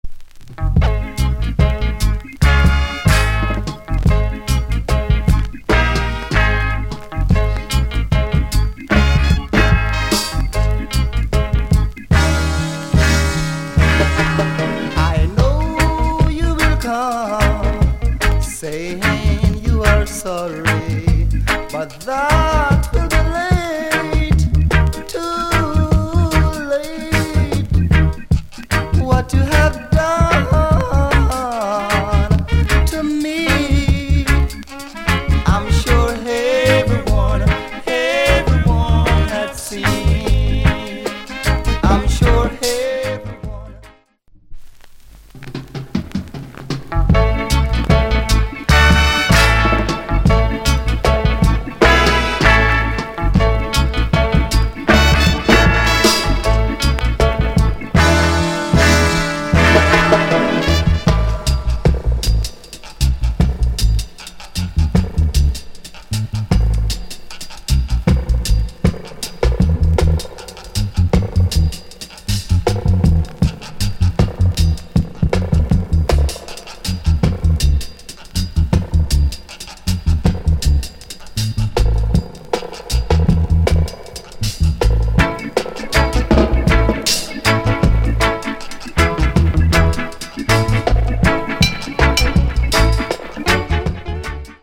Male Vocal Condition EX- Soundclip